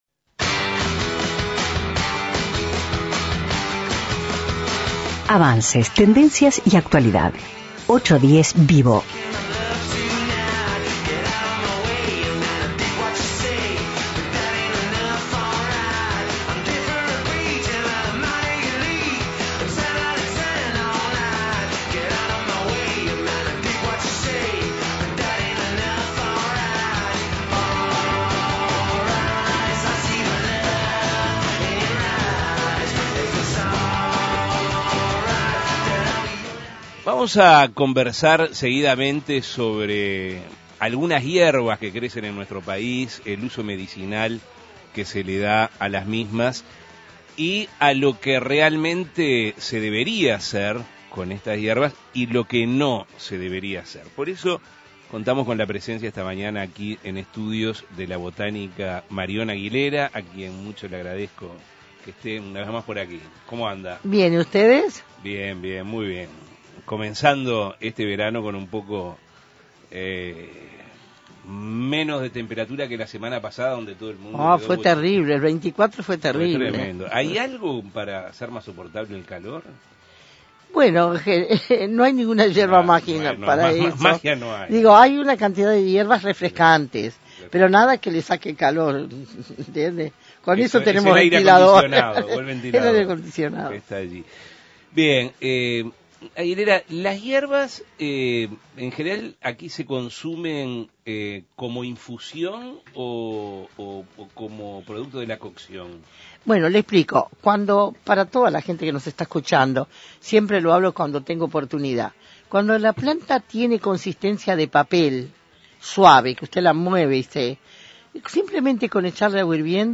810VIVO Avances, tendencia y actualidad recibió en estudios